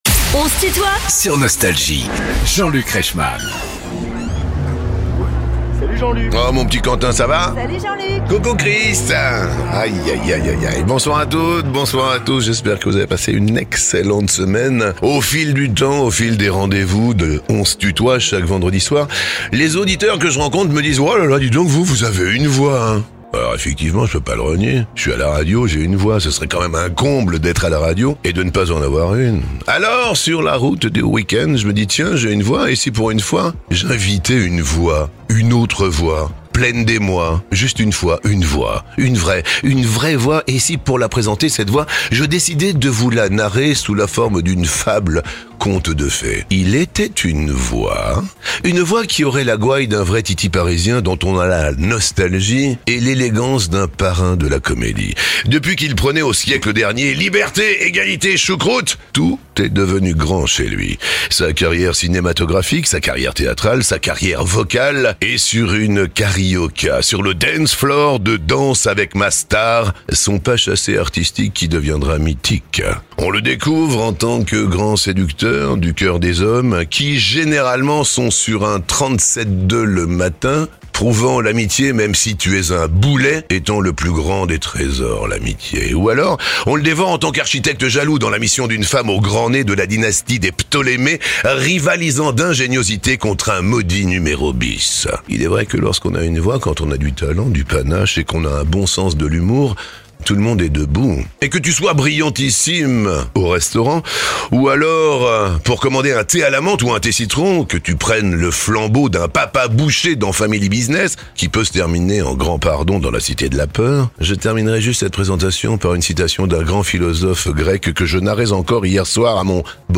Gérard Darmon invité de "On se tutoie ?..." avec Jean-Luc Reichmann (Partie 1) ~ Les interviews Podcast